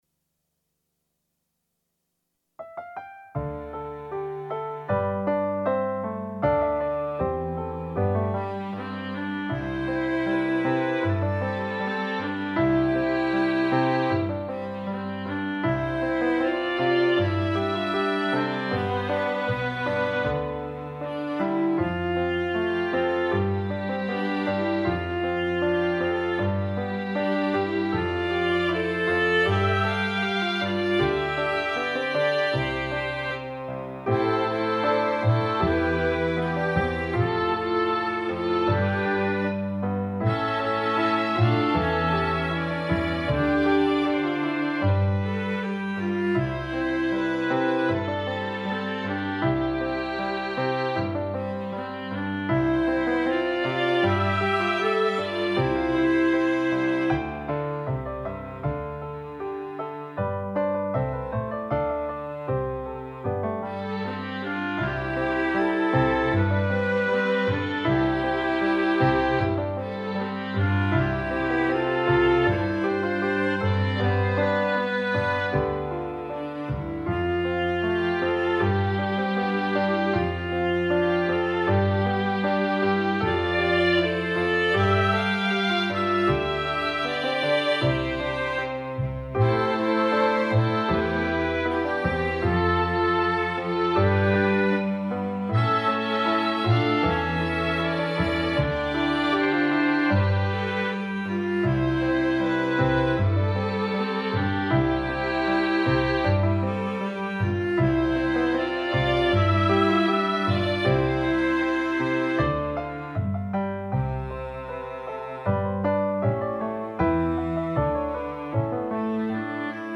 An original Christmas carol for Sop and Alto
with Keyboard accompaniment
and chimes / glockenspiel.